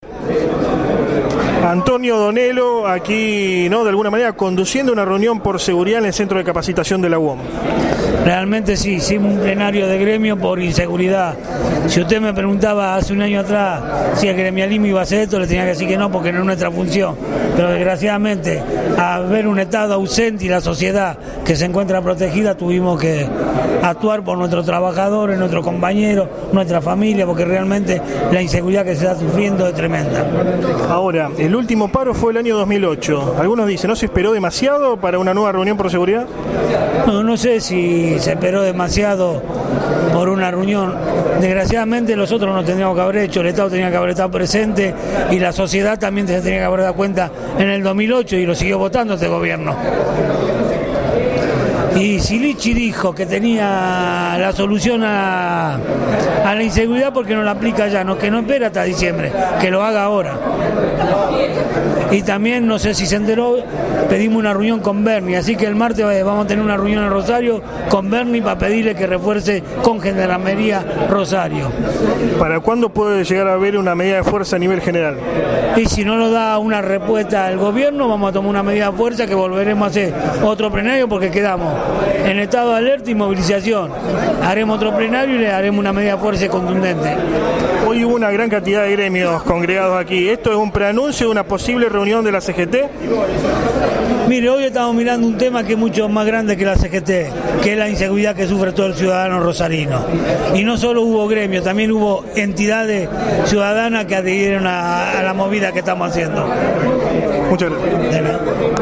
AUDIO ENTREVISTA
Cuna de la Noticia estuvo presente en la reunión abierta que mantuvieron diversos gremios con motivo de la honda inseguridad que vive la ciudad.